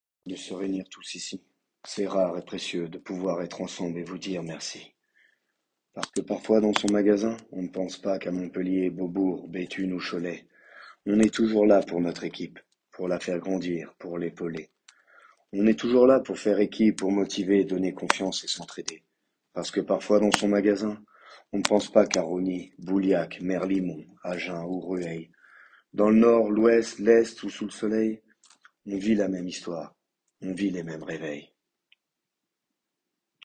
slam 1